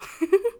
qyh银铃般5.wav 0:00.00 0:00.60 qyh银铃般5.wav WAV · 52 KB · 單聲道 (1ch) 下载文件 本站所有音效均采用 CC0 授权 ，可免费用于商业与个人项目，无需署名。
人声采集素材